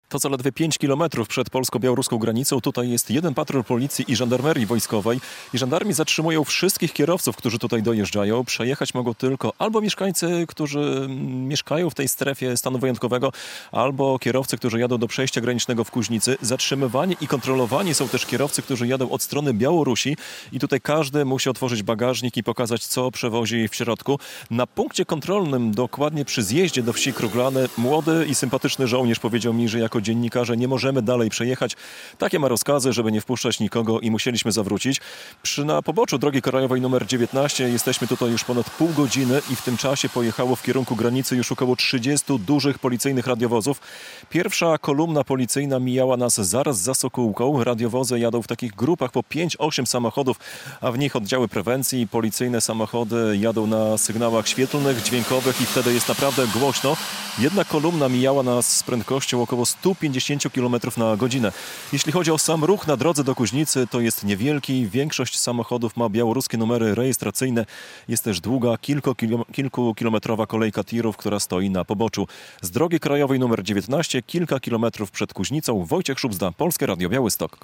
Policjanci i żandarmeria wojskowa kontrolują auta wjeżdżające do Kuźnicy - relacja